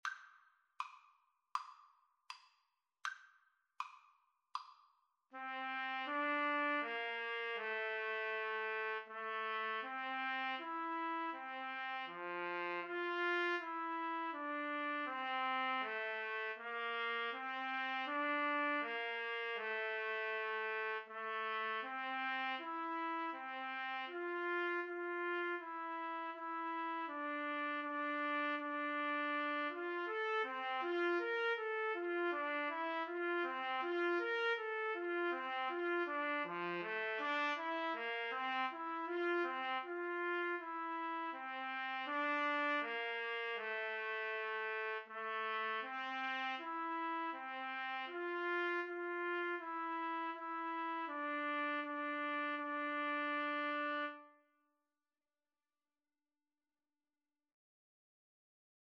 Andante Espressivo = c. 80
4/4 (View more 4/4 Music)